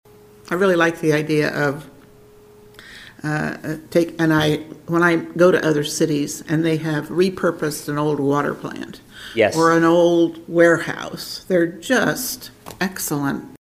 Commissioner Linda Morse.